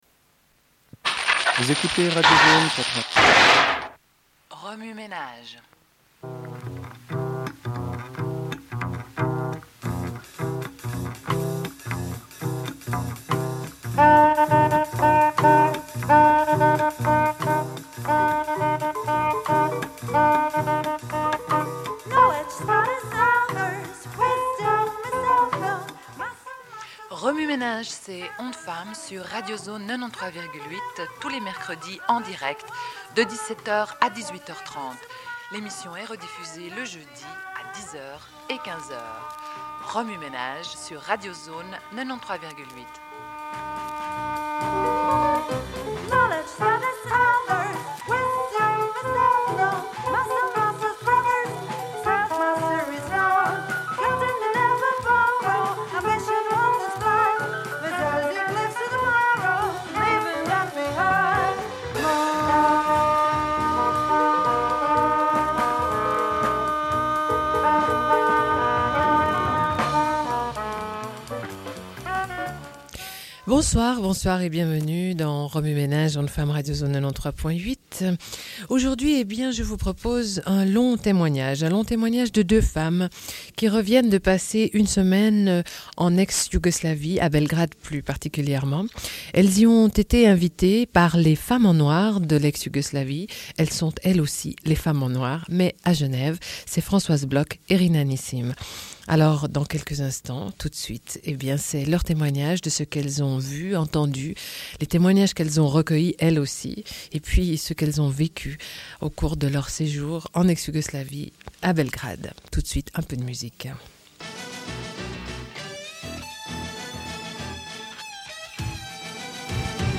Une cassette audio, face A31:23
Radio